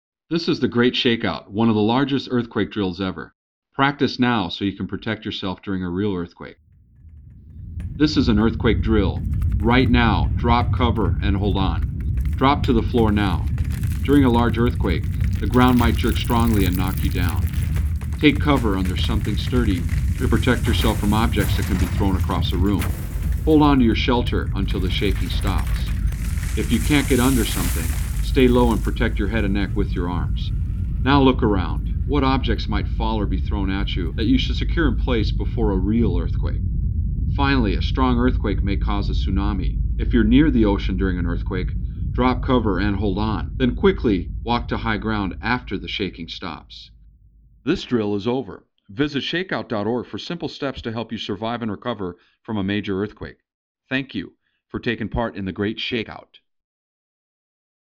For best performance, listen to the recording through external speakers (there is a rumble sound that may not be heard through most internal computer speakers).
ShakeOutDrillBroadcastEnglish.aif